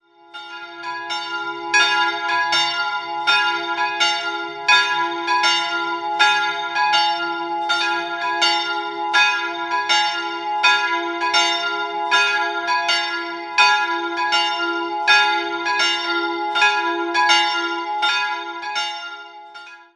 Der barocke Zwiebelturm kam 1756 hinzu. 2-stimmiges Geläut: f''-as'' Die kleinere Glocke stammt von Karl Czudnochowsky aus dem Jahr 1950, die größere von Rudolf Perner aus dem Jahr 1992.